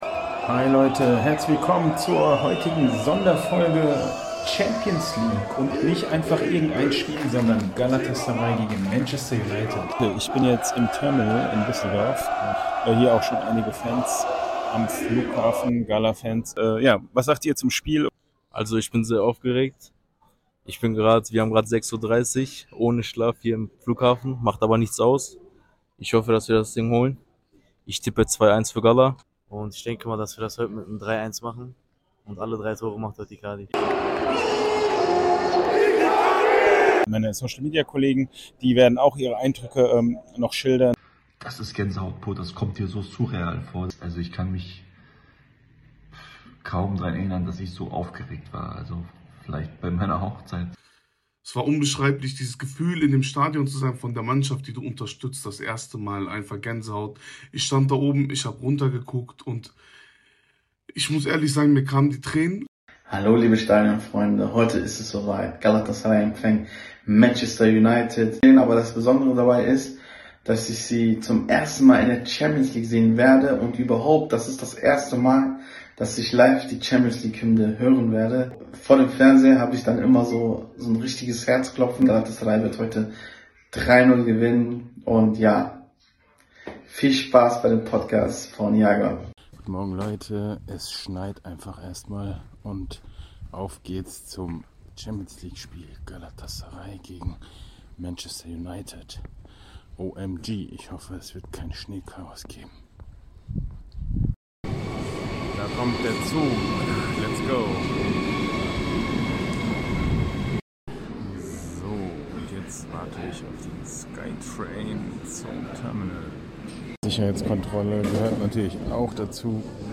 Podcast Sonderfolge Championsleague Galatasaray vs. Manchester United Live aus Istanbul ~ Futsal Podcast